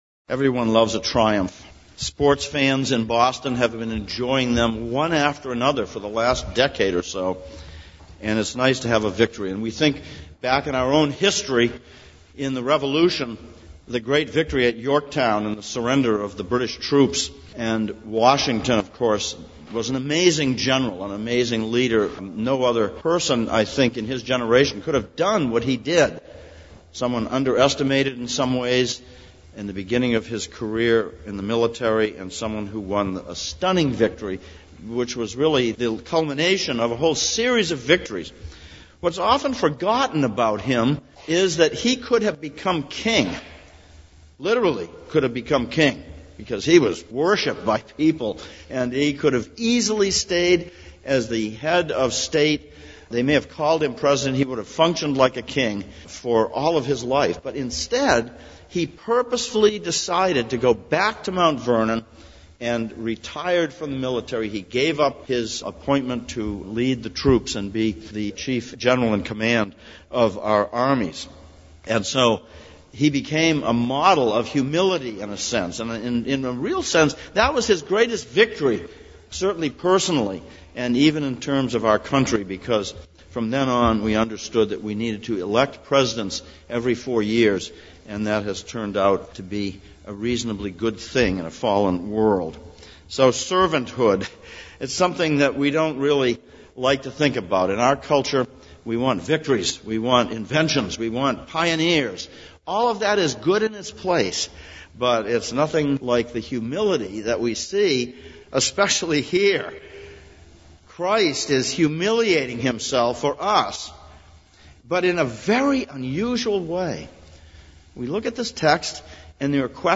Palm Sunday Passage: Psalm 118:1-29, Matthew 21:1-11 Service Type: Sunday Morning « 19.